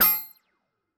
Futuristic Device Glitch (5).wav